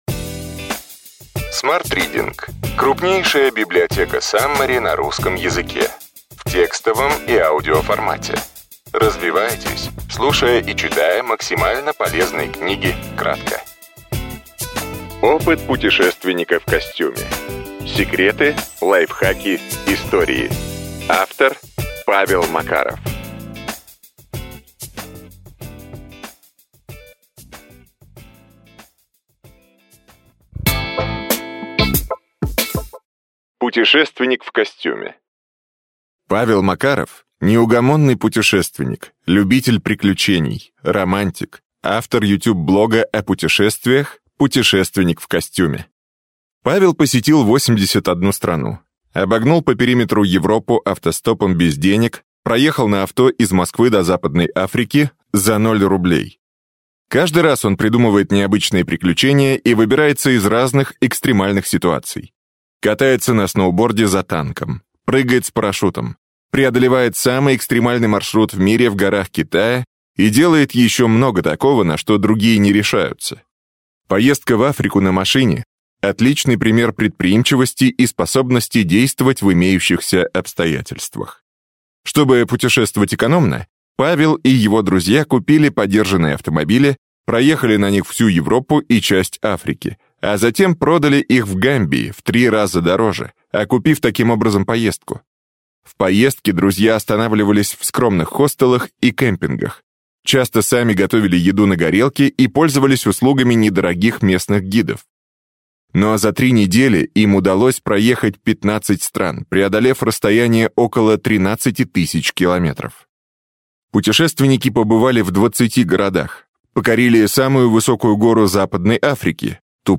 Аудиокнига Опыт путешественника в костюме: секреты, лайфхаки, истории.